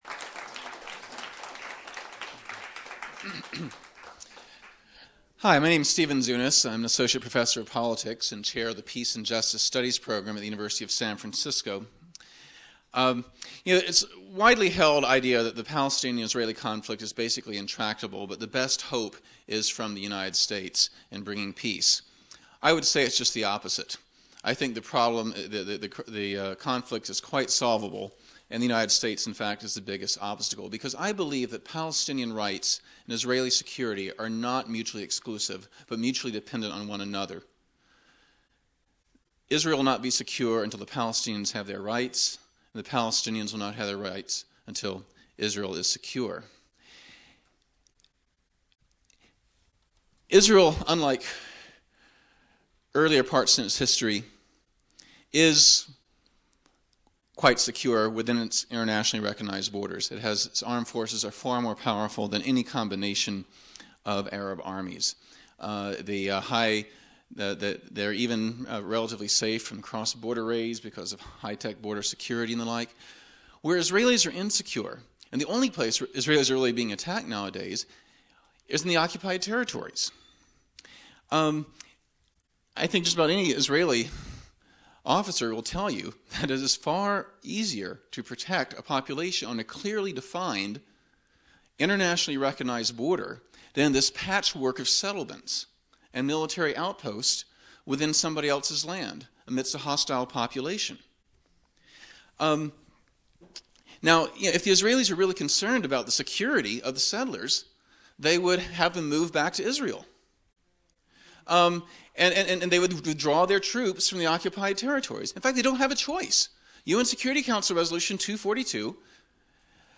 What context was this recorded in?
On Tuesday night 10/24/00 there was a discussion at UCSC about the recent outbreak of violence in Israel and Palestinian territories. Audio - UCSC Panel Discussion about the Israel/Palestinian Conflict (3 of 5)